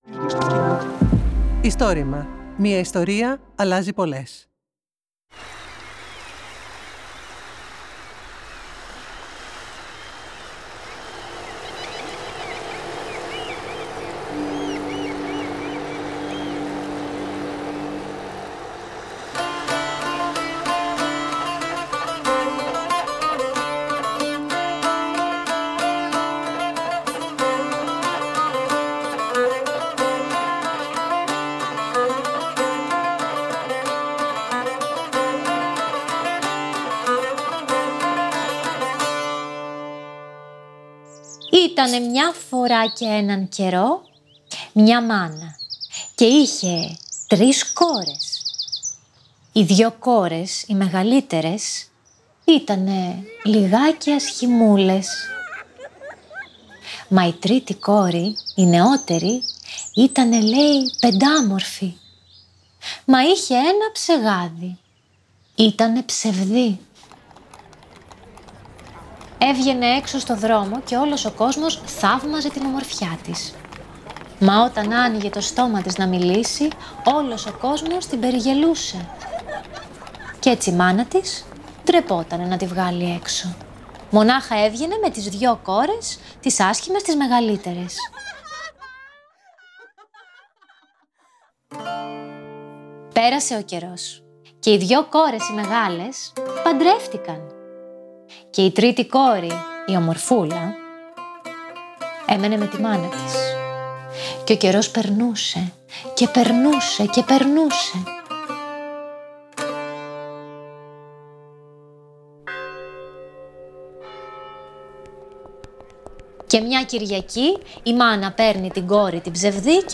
Η ψευδή βασιλοπούλα: Παραμύθι από την Κύθνο | Κυριακή 28 Απριλίου 2024
Το Istorima είναι το μεγαλύτερο έργο καταγραφής και διάσωσης προφορικών ιστοριών της Ελλάδας.